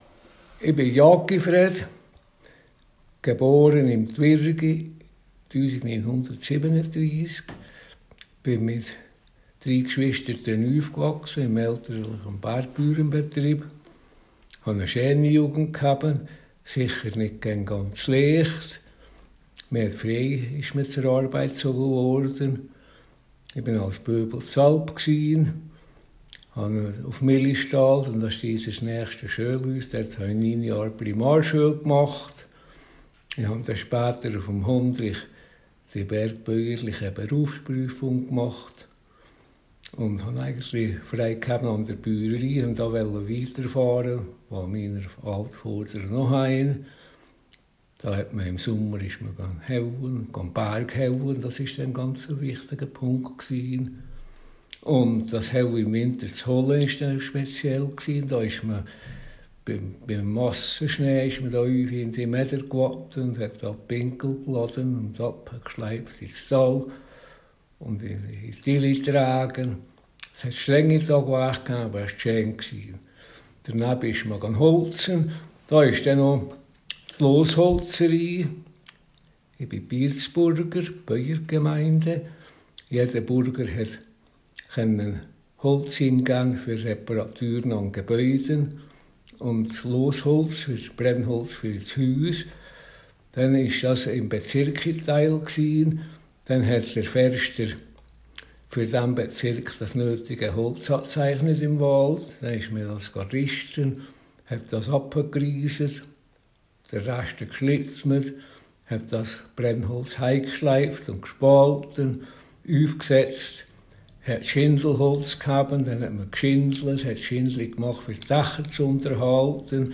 Hasli-Dytsch